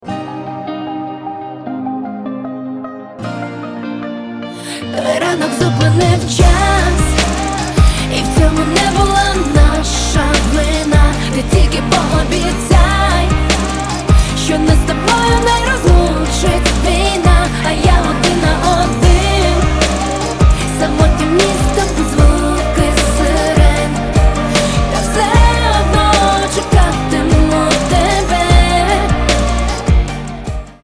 поп , романтические
красивый женский голос